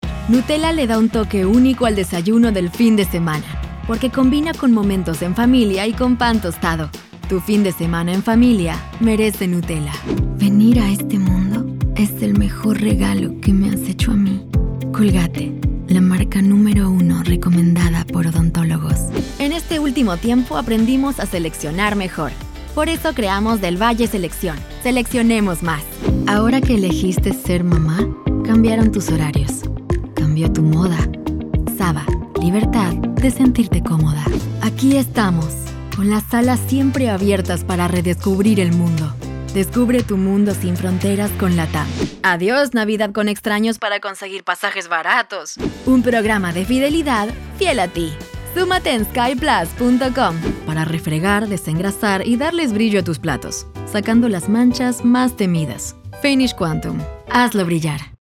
Professional Voice talent specialized in Neutral LATAM spanish
Neutral Latam Spanish Commercial